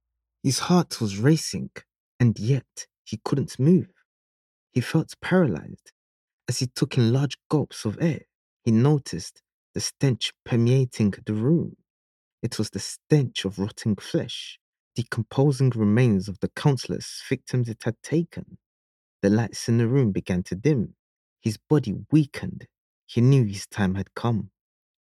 Best Male Voice Over Actors In January 2026
English (Caribbean)
Yng Adult (18-29) | Adult (30-50)